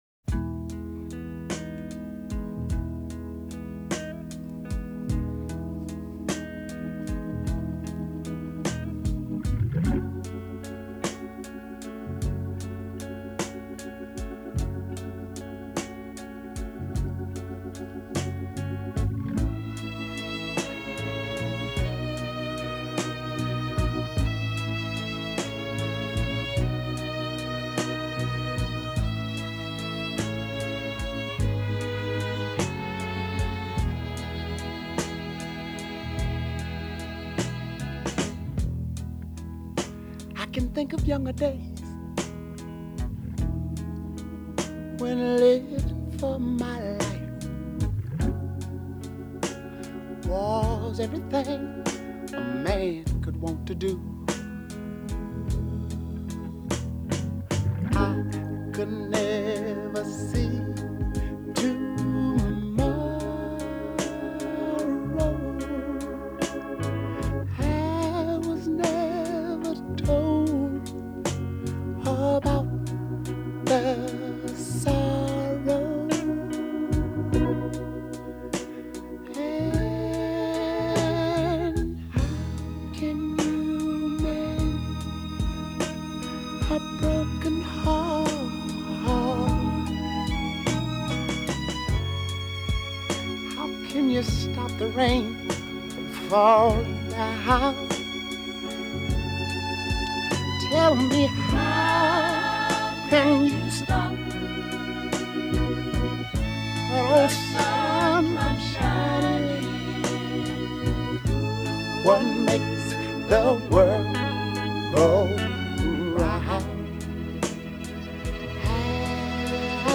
gently arpeggiating triplets cascading in the background